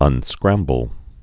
(ŭn-skrămbəl)